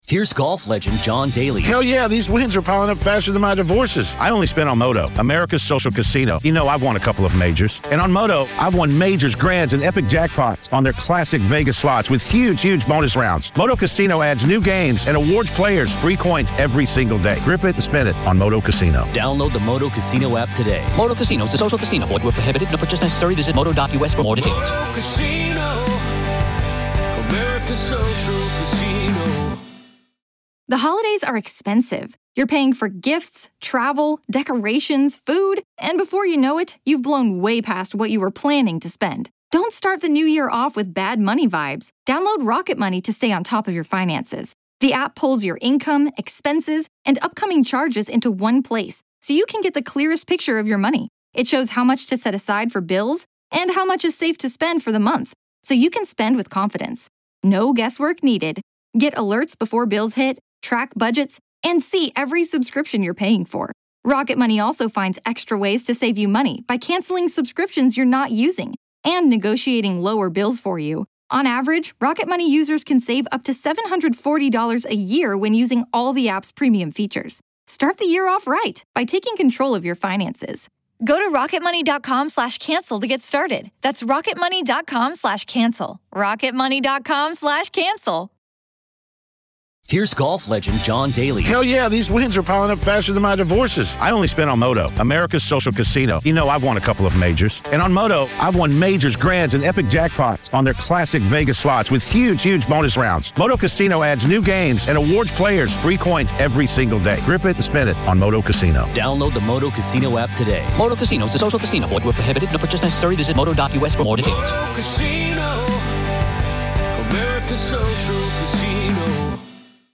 Haunting real ghost stories told by the very people who experienced these very real ghost stories.